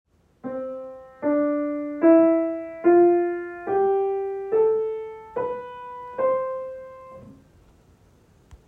Example 4.3.1. Major scale
Major scale built by combining two major tetrachords. Major tetrachord number 1 consists of C, D, E, and F. A whole step above F is G. Major tetrachord number 2 consists of G, A, B, and C. Joining the two major tetrachord together with a whole step in between creates a C major scale.